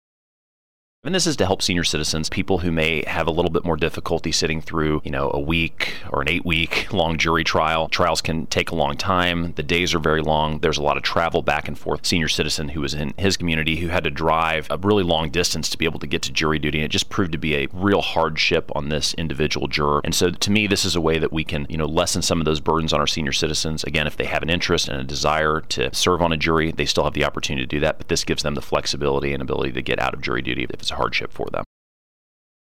3. Senator Luetkemeyer also says Senate Bill 297 is a measure that would allow individuals 75 years of age or older to be excused from jury duty.